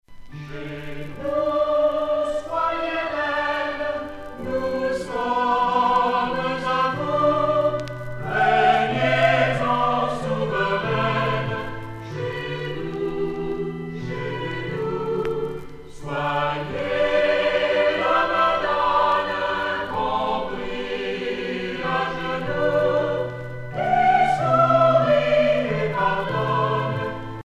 prière, cantique
Genre strophique
Pièce musicale éditée